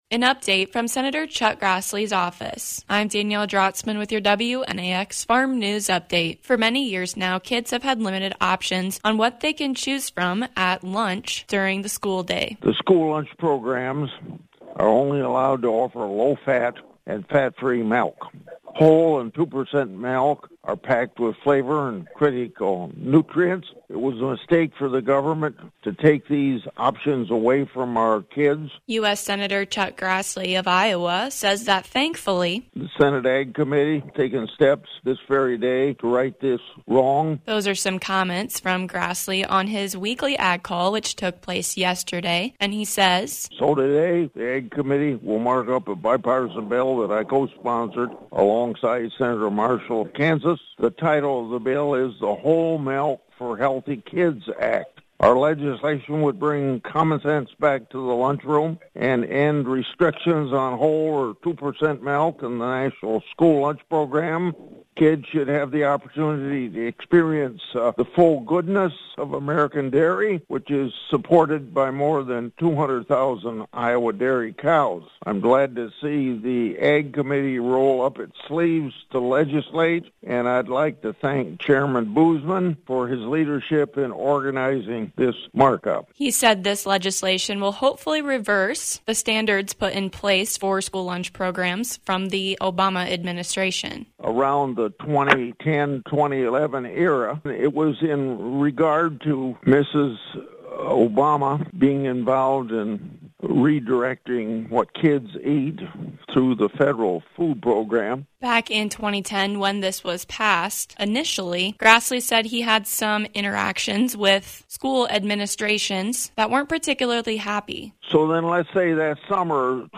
Senator Chuck Grassley fills us in on proposed legislation to get a variety of milk options in school lunch lines.